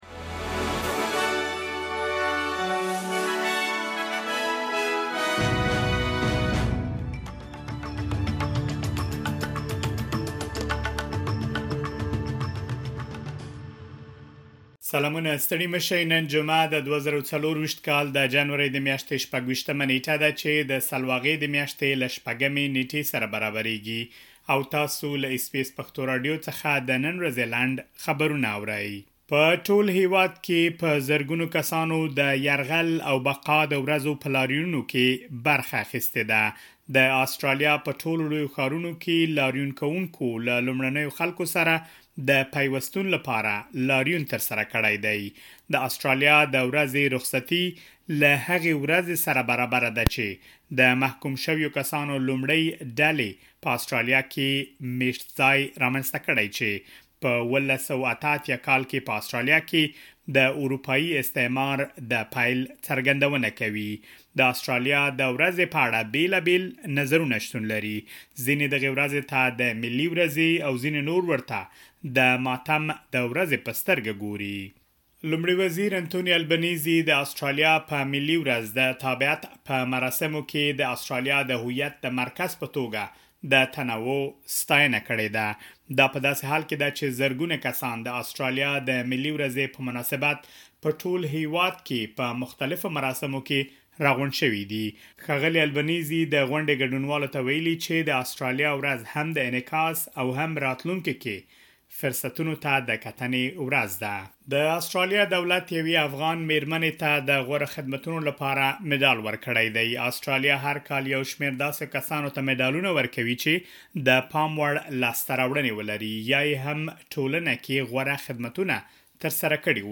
د اس بي اس پښتو راډیو د نن ورځې لنډ خبرونه دلته واورئ.